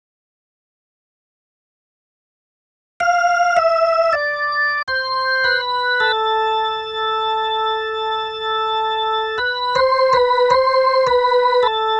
Organ 02.wav